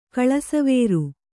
♪ kaḷasavēru